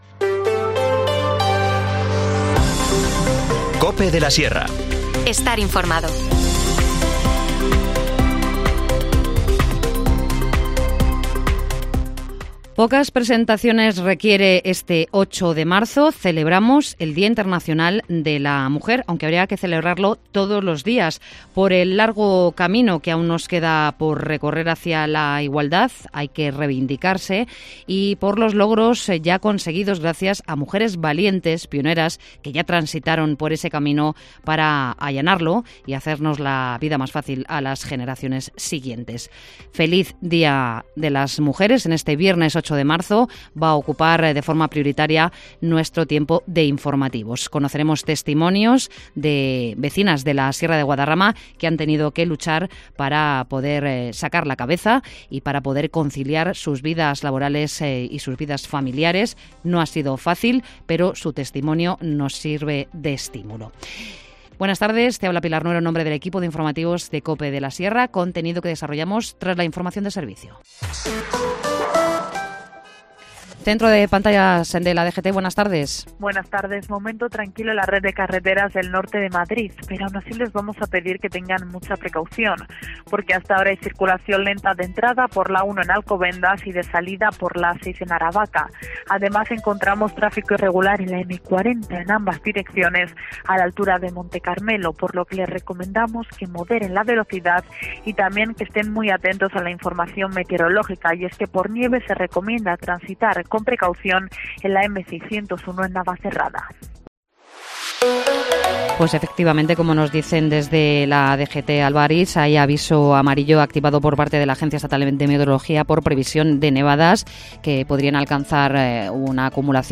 Informativo | Mediodía en COPE de la Sierra, 8 de marzo de 2024